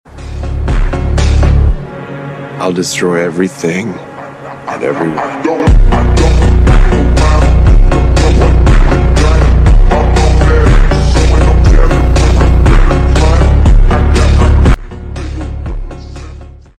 Seat Leon St Cupra 300 sound effects free download
Seat Leon St Cupra 300 - Catless downpipe - Blaze Performance intake - Catback